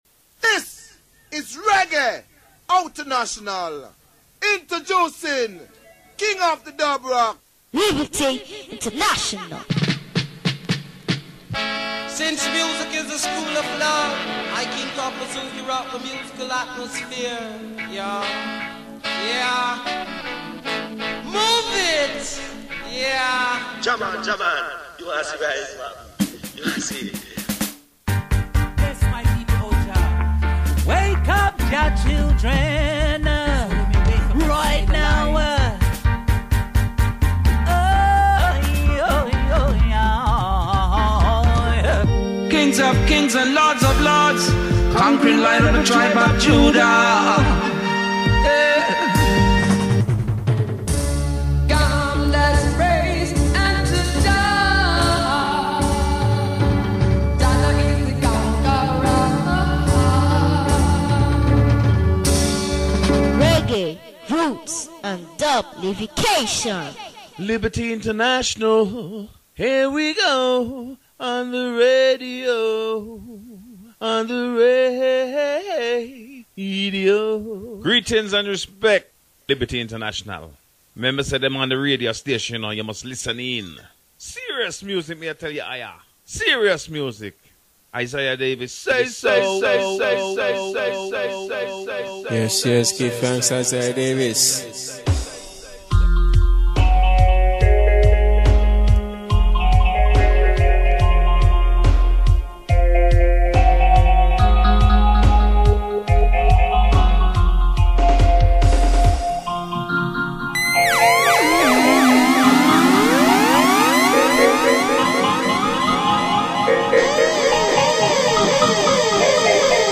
is broadcast Live & Direct every Saturday